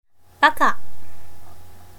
How to pronounce baka in Japanese?
pronunciation_ja_ばか.mp3